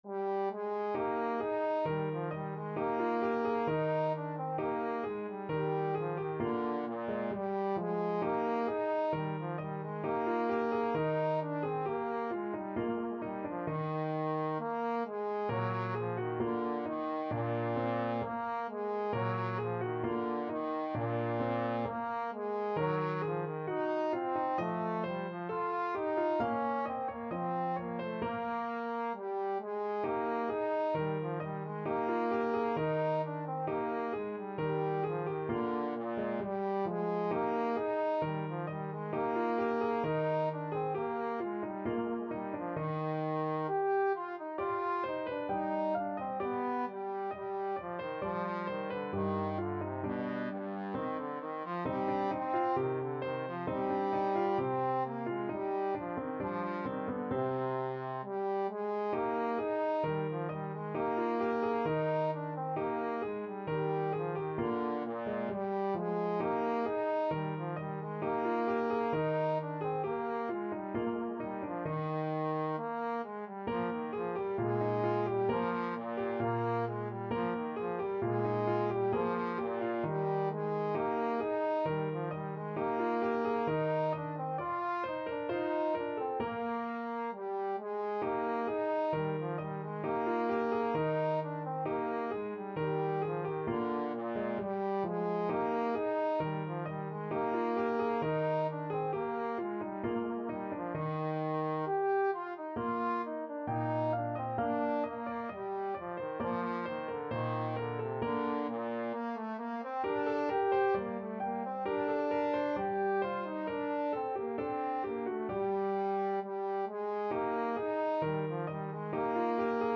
Trombone
G3-G5
Eb major (Sounding Pitch) (View more Eb major Music for Trombone )
2/2 (View more 2/2 Music)
~ = 100 Allegretto =c.66
Classical (View more Classical Trombone Music)
martini_gavotte12_TBNE.mp3